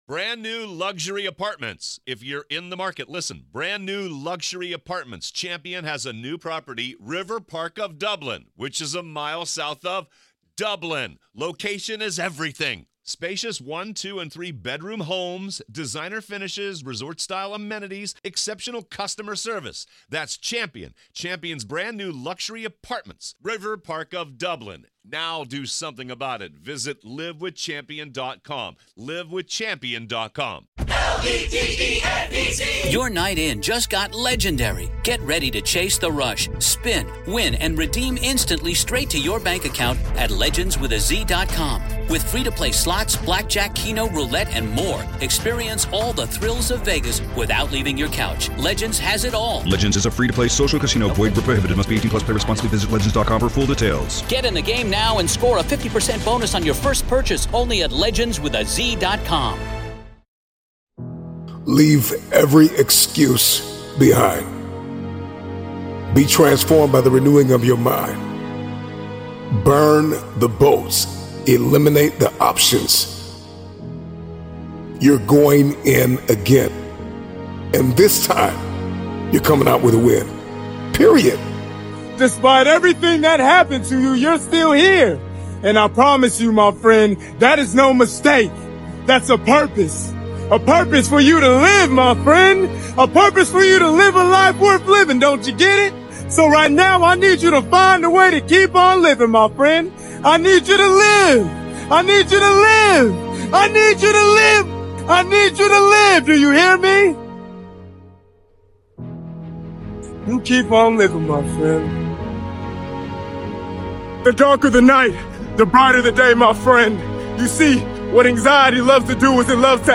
One of the Best Motivational Speeches of 2024